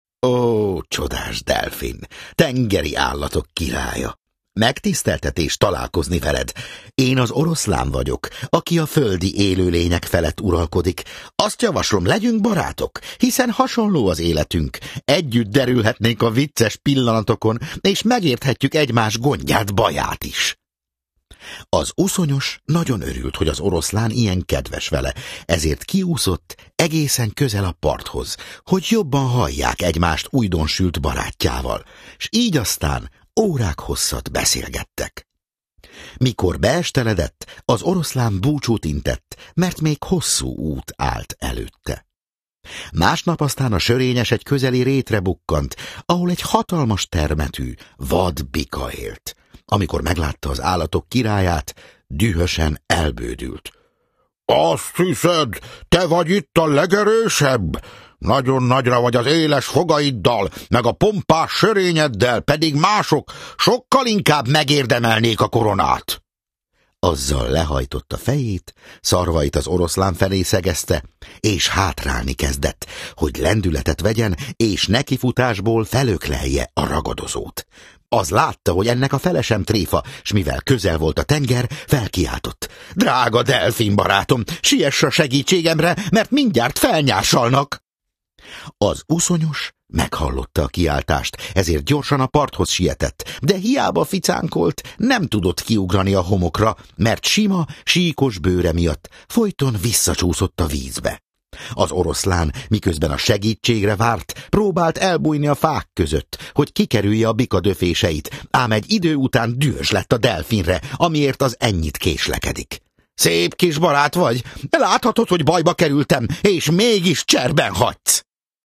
100 Ezópusz-mese (Online hangoskönyv) Kálloy Molnár Péter előadásában Hallgass bele!